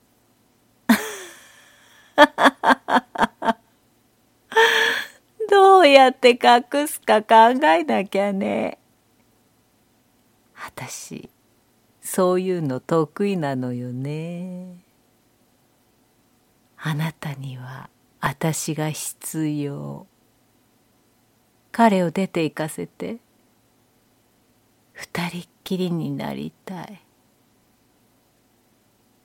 ボイスサンプル
色気